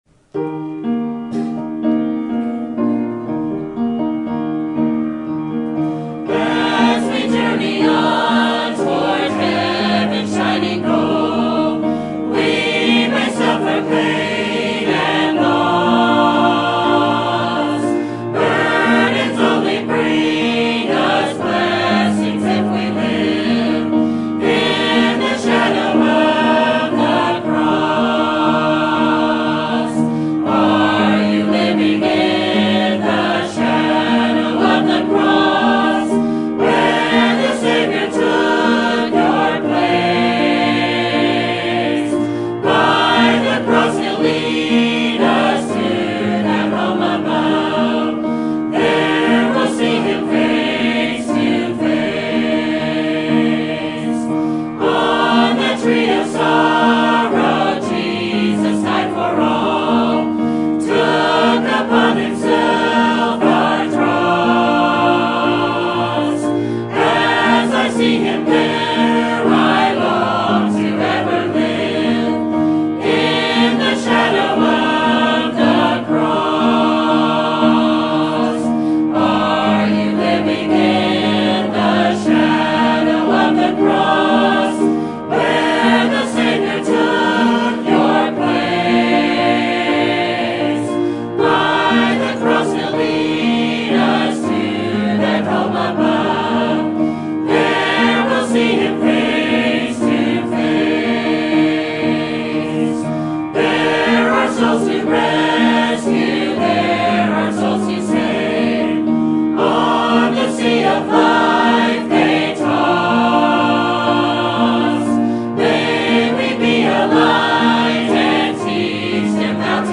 Sermon Topic: General Sermon Type: Service Sermon Audio: Sermon download: Download (29.09 MB) Sermon Tags: Deuteronomy Moses Generation Teaching